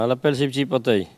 Elle crie pour appeler les canetons
Catégorie Locution